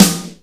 BRICK SNARE.WAV